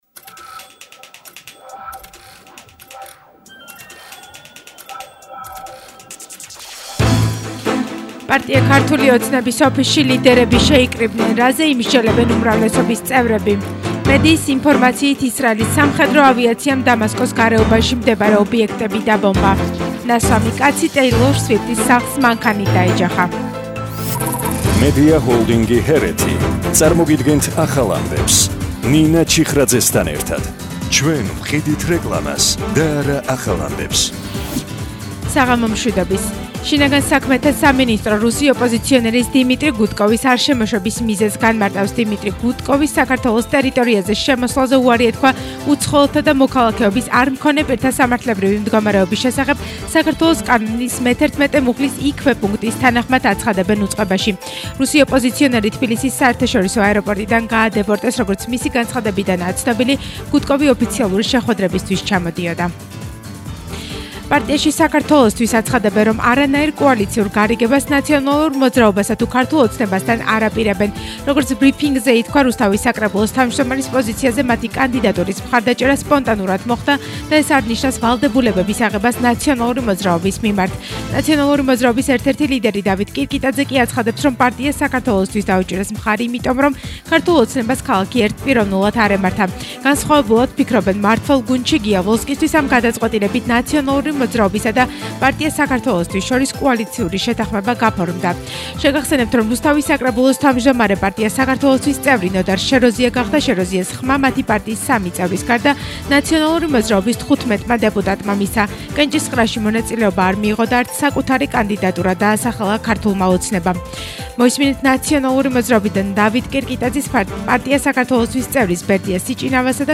ახალი ამბები 19:00 საათზე – 31/01/22